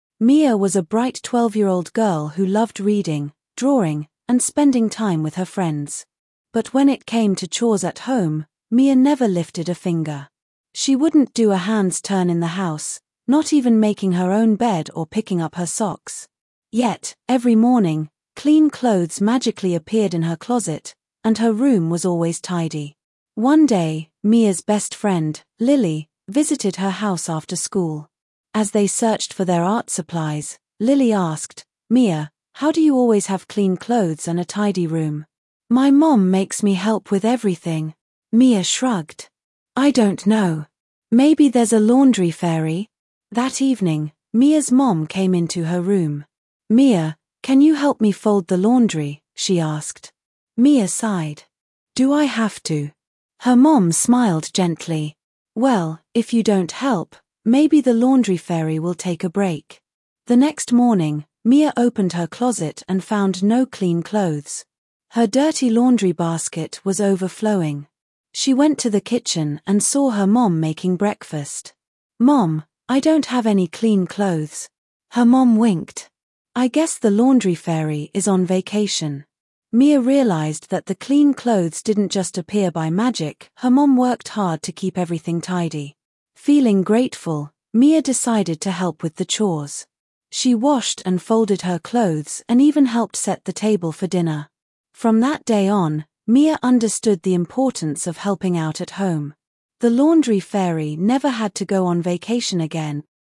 Każda historia z tej kolekcji nie jest przeznaczona tylko do czytania – jest również czytana na głos!
Each story in this collection isn’t just for reading—it’s also read aloud for you!